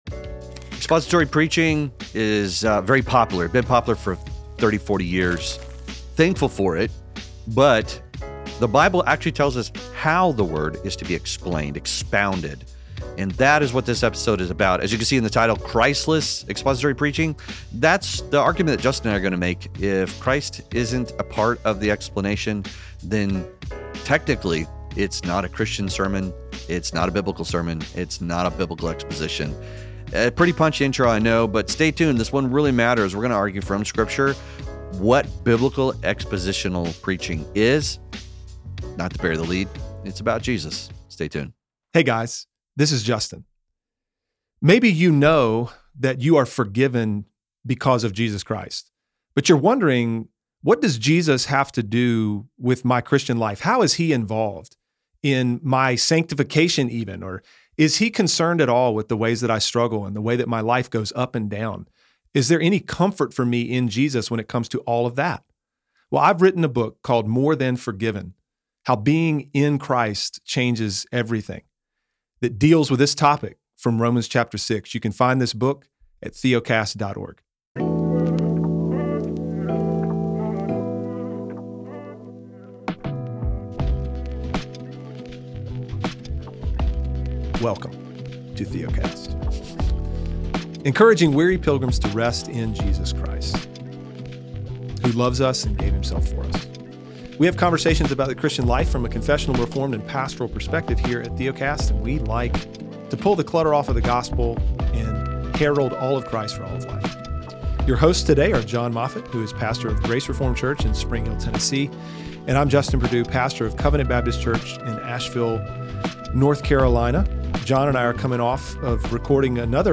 It is a bold claim, but this conversation really matters. We are going to argue from Scripture what biblical expositional preaching actually is.